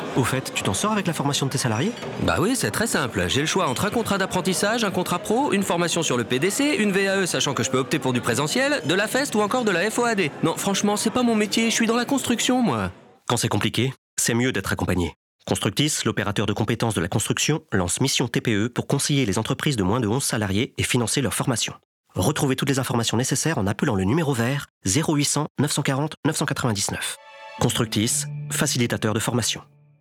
YOUZ_SPOT-RADIO_RTL_REGION.mp3